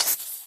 mob / creeper